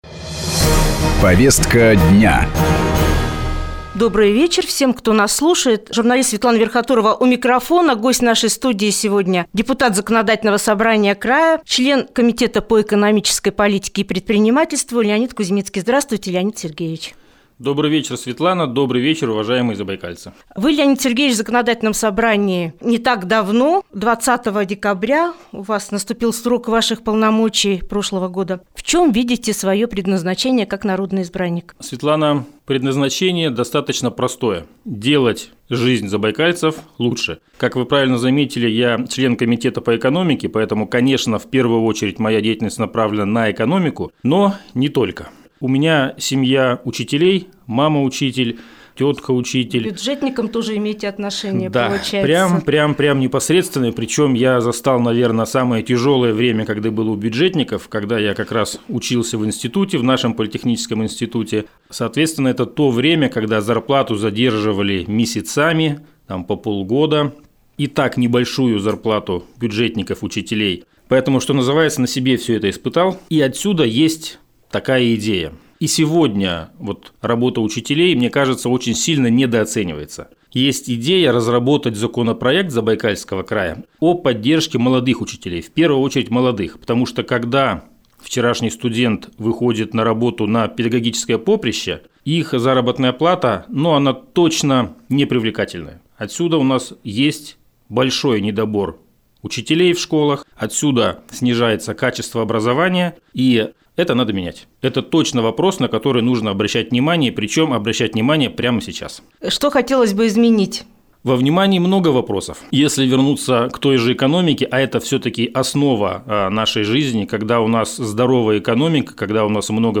В чем призвание депутата и как удается совмещать общественную работу с производственной деятельностью? – интервью члена комитета по экономической политике и предпринимательству Леонида Кузьмицкого